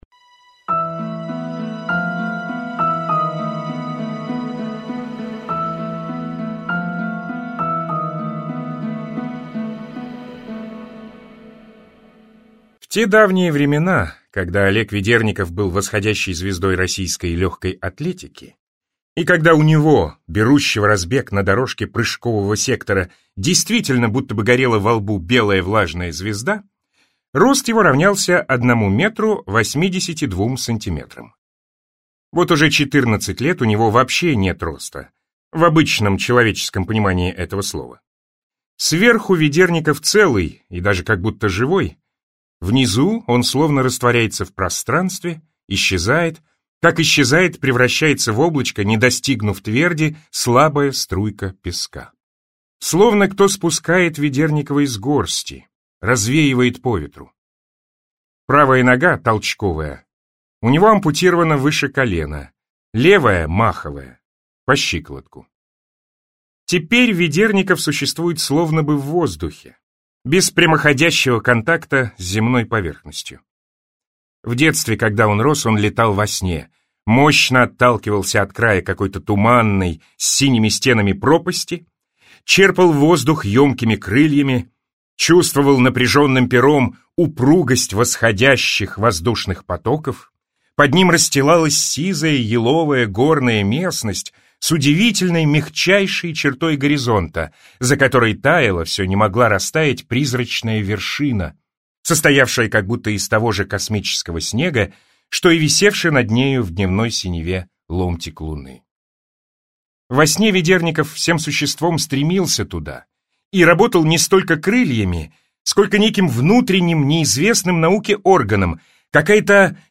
Аудиокнига Прыжок в длину | Библиотека аудиокниг